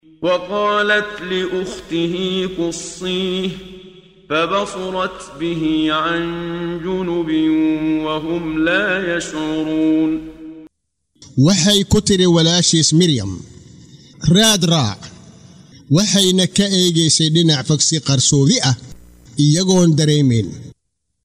Waa Akhrin Codeed Af Soomaali ah ee Macaanida Suuradda Al-Qasas ( Qisooyinka ) oo u kala Qaybsan Aayado ahaan ayna la Socoto Akhrinta Qaariga Sheekh Muxammad Siddiiq Al-Manshaawi.